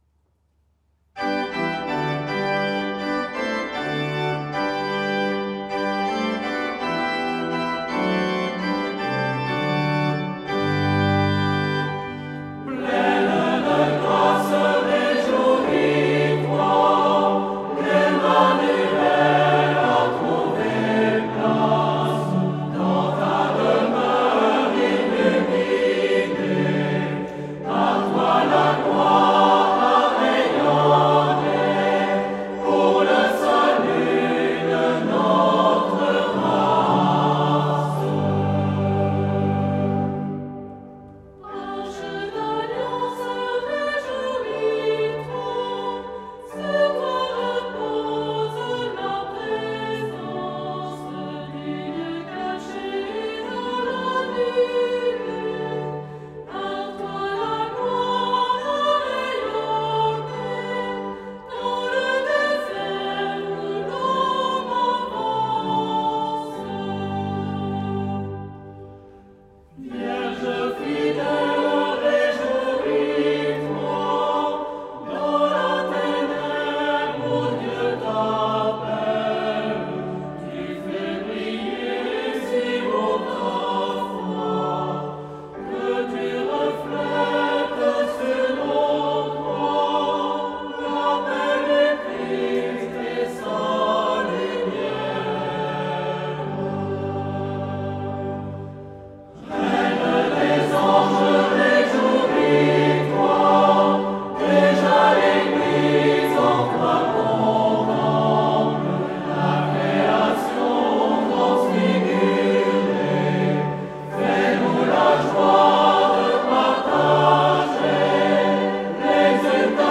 Chant à Marie